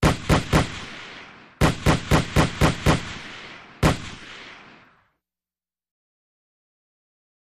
Automatic Weapon 4, Single & Multiple Bursts, Echoey.